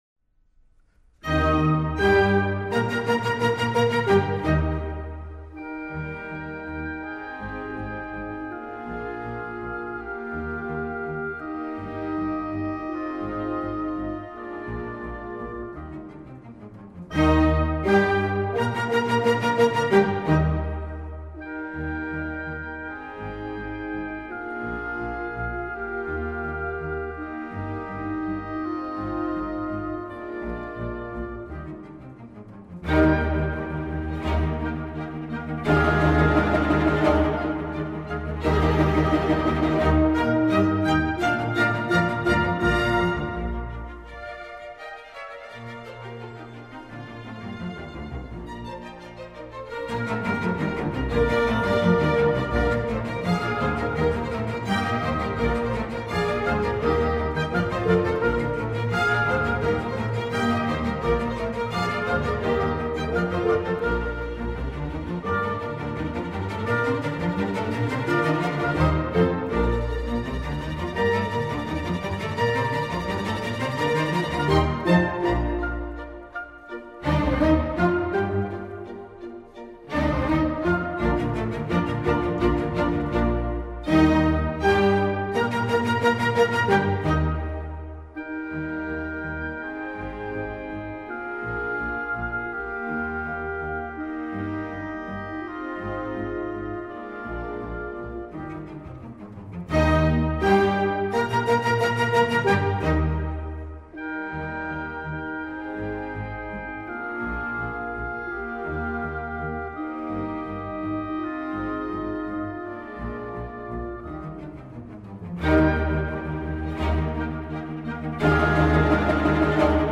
The three-part work – molto allegro, andante, presto – shows the influence of several composers, including his father and the sons of JS Bach, especially Johann Christian Bach, an important early symph